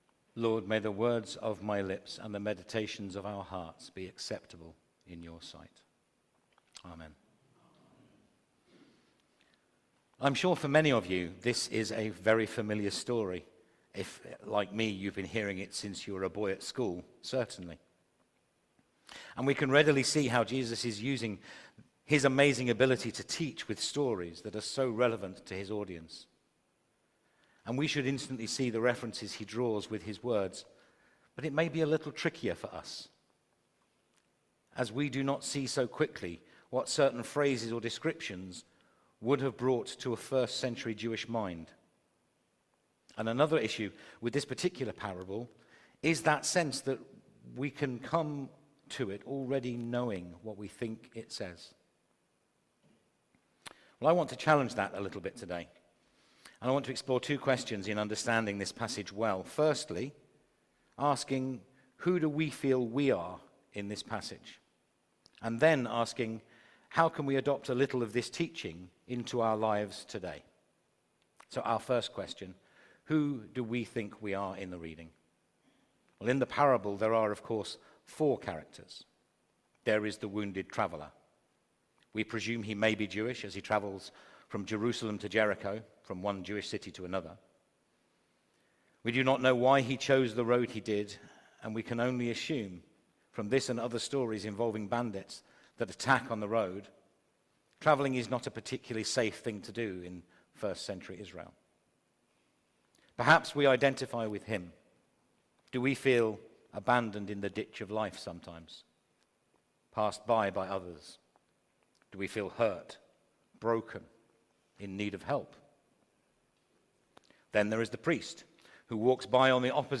Media for Holy Communion on Sun 13th Jul 2025 09:00 Speaker
Theme: Putting the Gospel into action Sermon Search